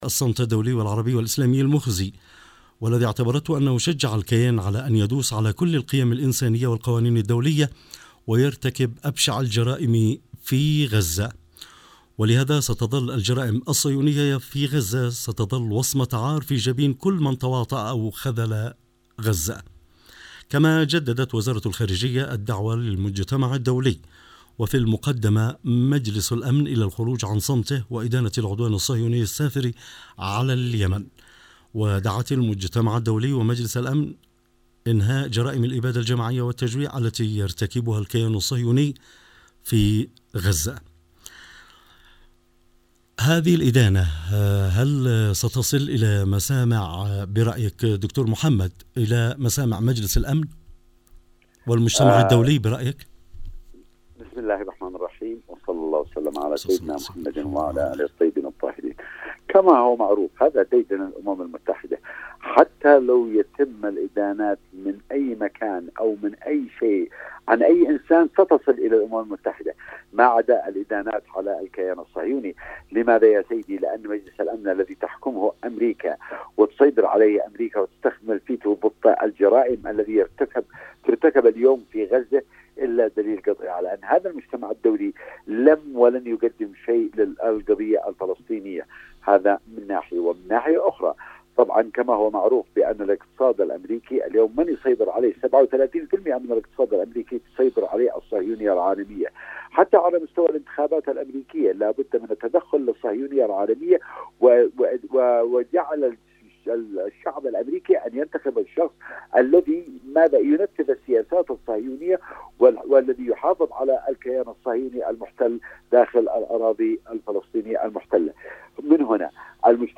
☎ لقاء عبر الهاتف لبرنامج العدوان وحدنا عبر إذاعة صنعاء البرنامج العام والإذاعات الوطنية المرتبطة مع